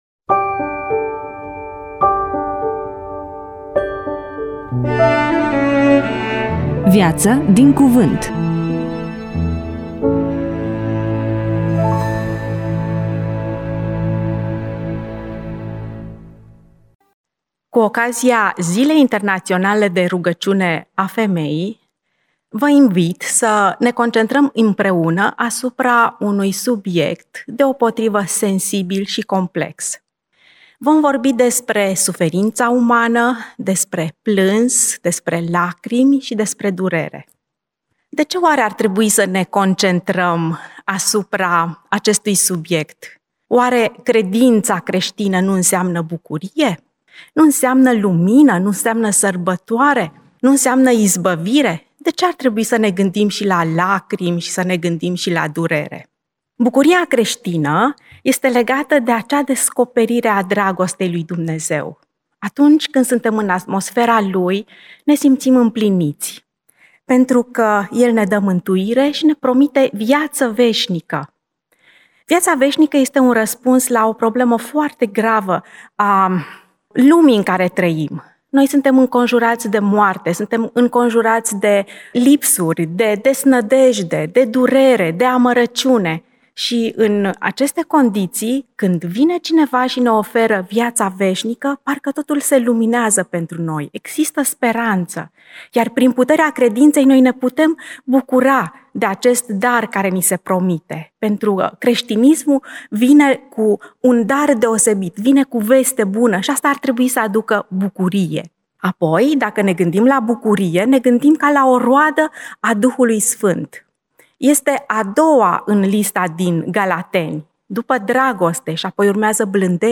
EMISIUNEA: Predică DATA INREGISTRARII: 21.03.2026 VIZUALIZARI: 18